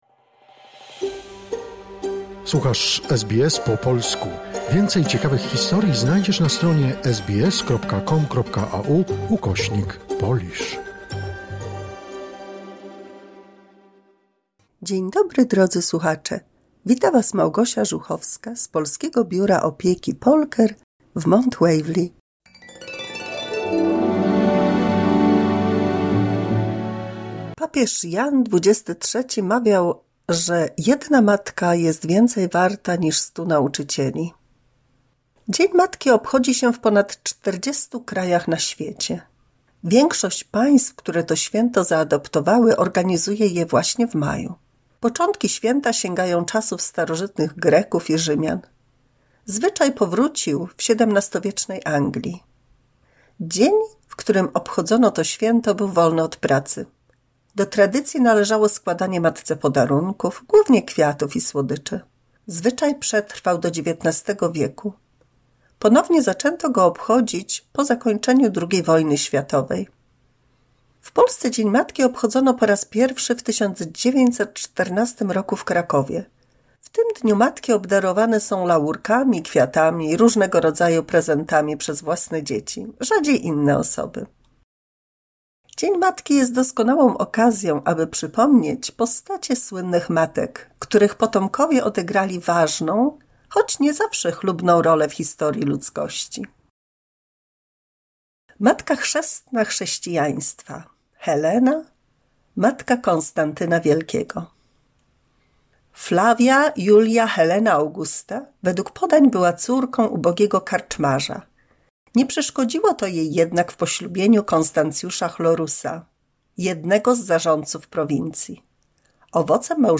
W 207 mini słuchowisku dla polskich seniorów, z okazji zbliżającego się polskiego Dnia Matki (obchodzonego co roku 26 maja), o słynnych matkach, których potomkowie odegrali ważne role w historii ludzkości.